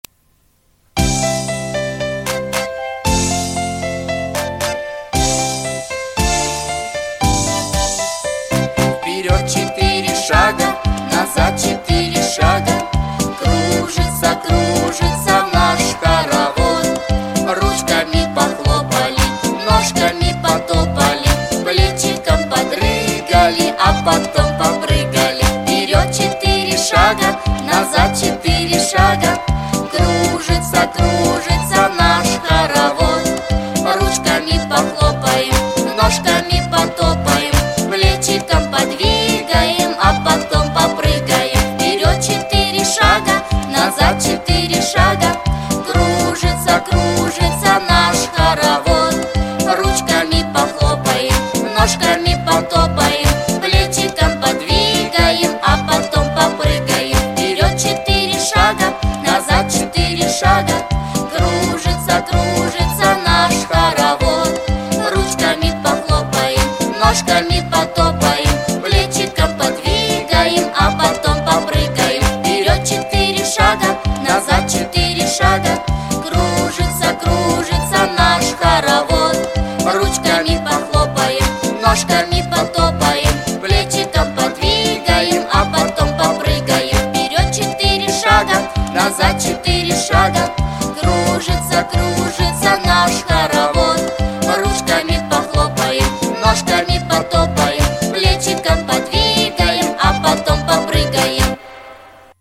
• Категория: Детские песни
теги: зарядка, детский сад, малышковые